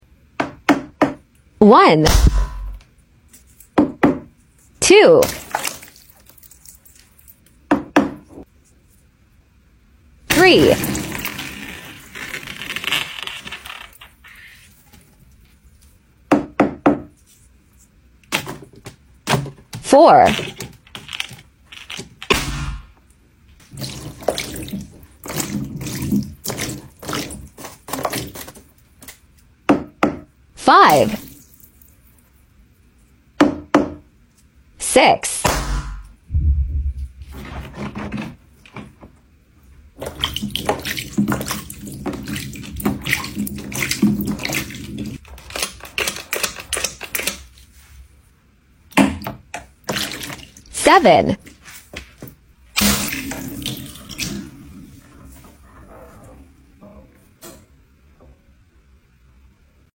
Satisfying Orbeez Balloon vs Dart